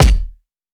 Kicks
Dilla Kick 22.wav